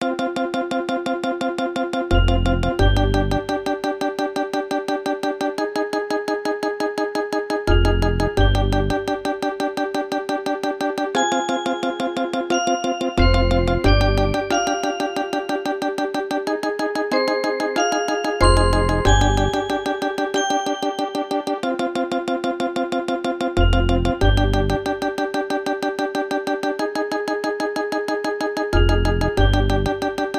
Converted from .mid to .ogg